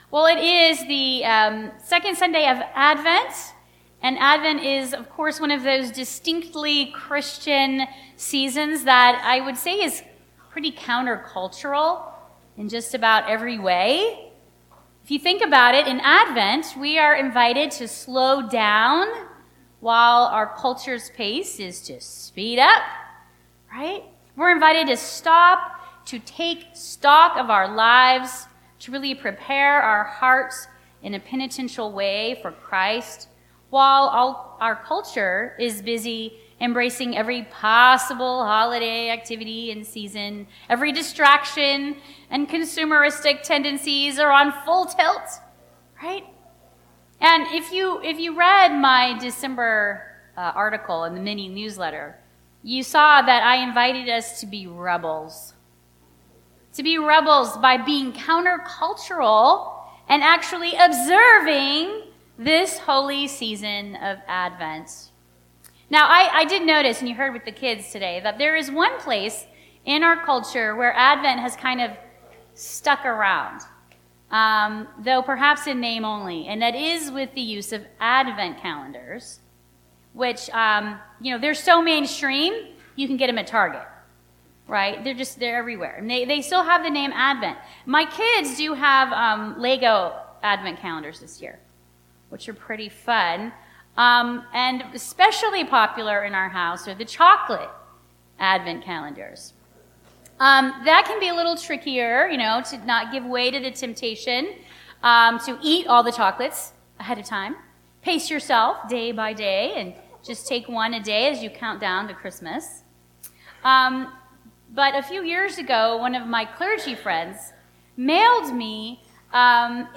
Listen to our recorded sermons in high-quality .mp3 format.